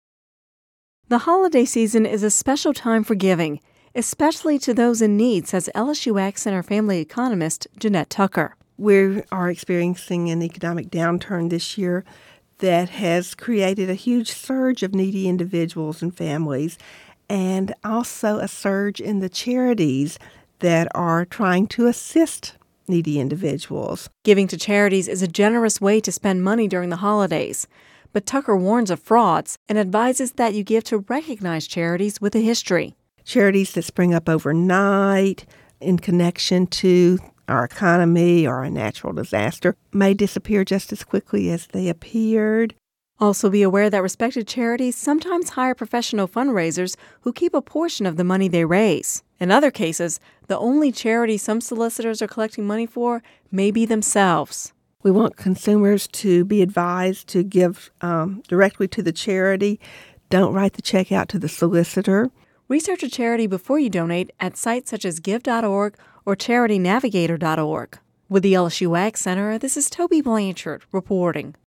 (Radio News 12/15/10) The holiday season is a special time for giving